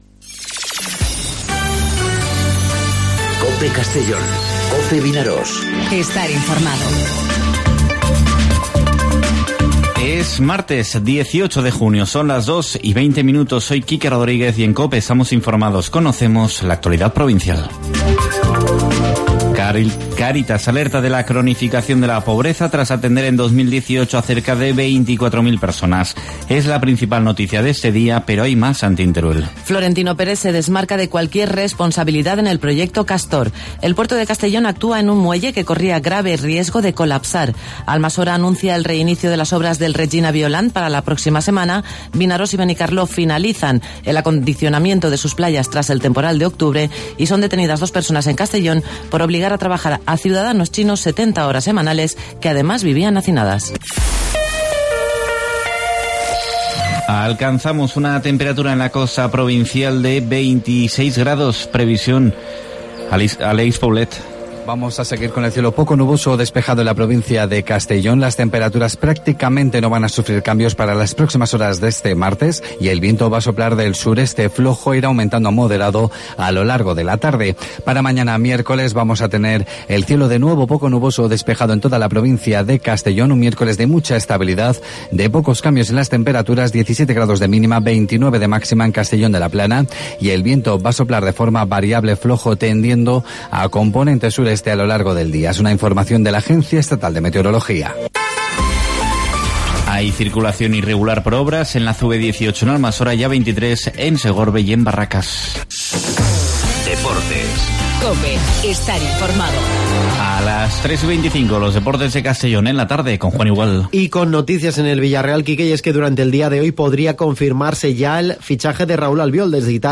Informativo 'Mediodía COPE' en Castellón (18/06/2019)